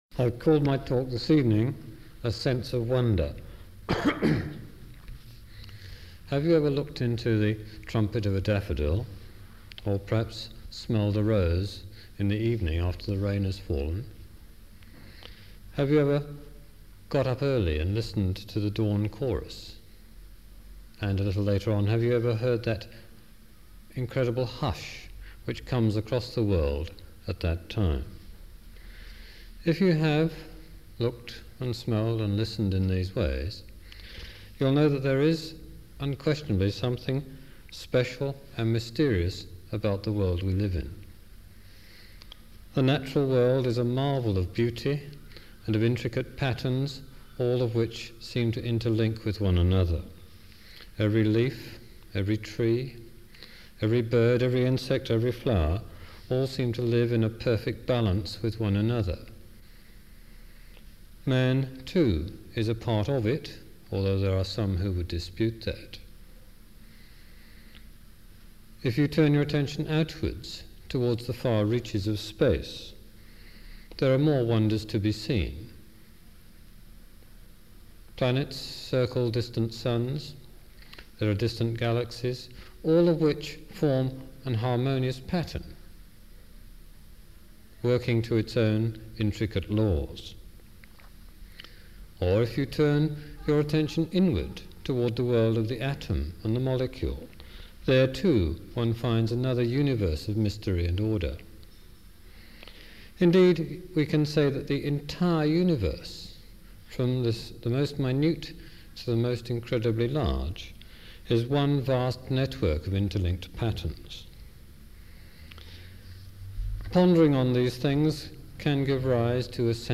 This talk was originally given in July 1988